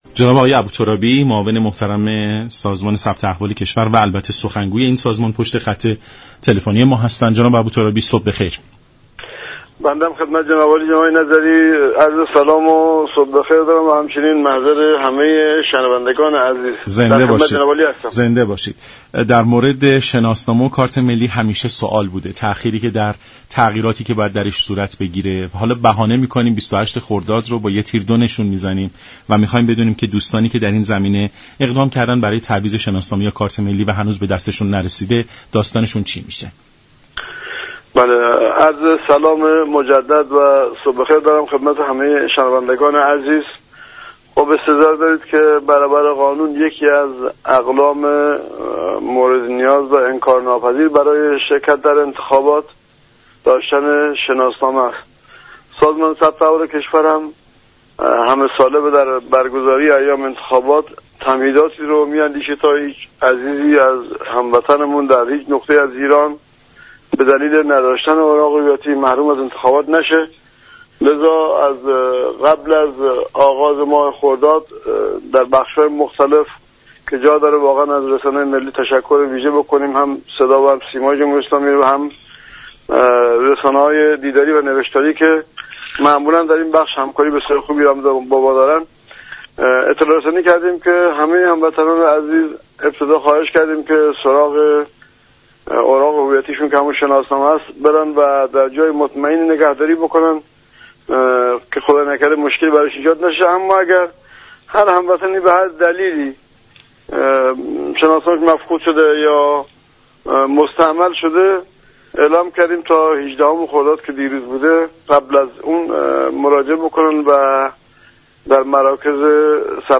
به گزارش شبكه رادیویی ایران، سیف الله ابوترابی معاون سازمان ثبت احوال كشور در برنامه سلام صبح بخیر شناسنامه را یكی از اقلام مورد نیاز برای شركت در انتخابات خواند و گفت: شناسنامه كسانی كه به هر علت دچار مشكل شده تا 18 خرداد فرصت داشته اند با مراجعه به مراكز ثبت احوال یا دفاتر پیشخوان مراحل ثبت نام را انجام دهند.